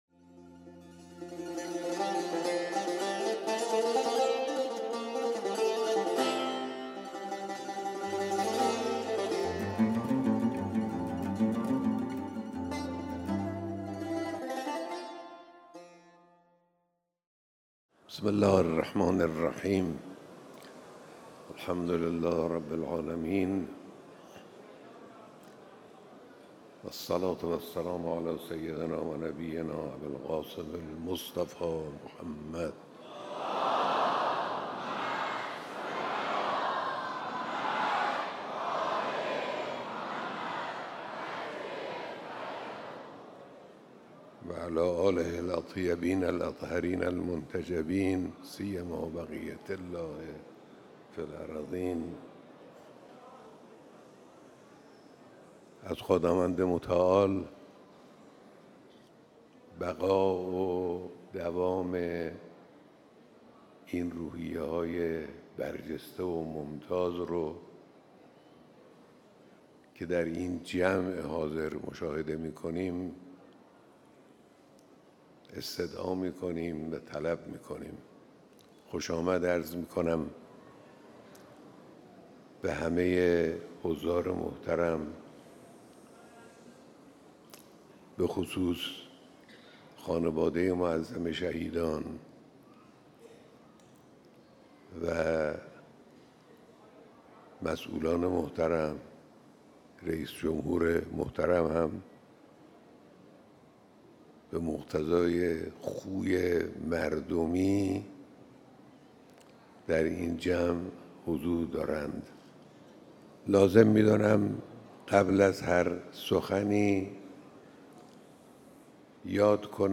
بیانات در دیدار هزاران نفر از مردم آذربایجان شرقی